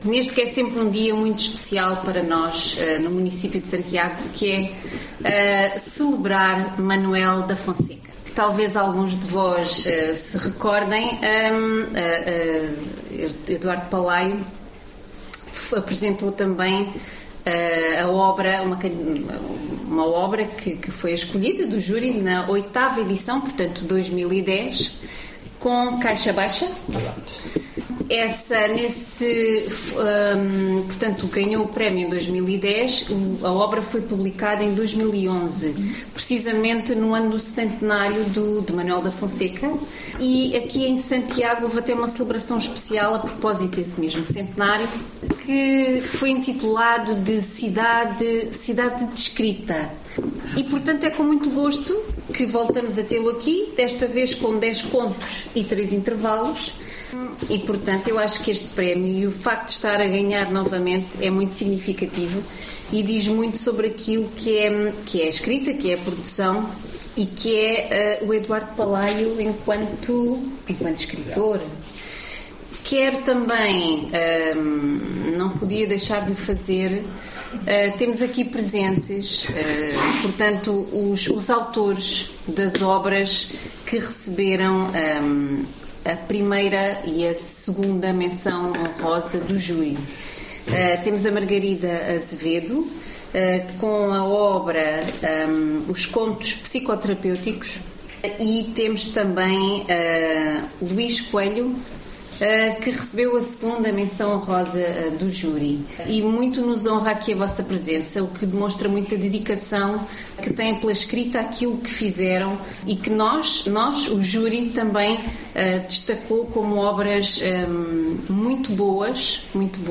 A cerimónia de entrega do Prémio de Conto Manuel da Fonseca 2024 decorreu, dia 19 de outubro, na Biblioteca Municipal Manuel da Fonseca, em Santiago do Cacém.
Declarações da Vereadora da Câmara Municipal de Santiago do Cacém, Sónia Gonçalves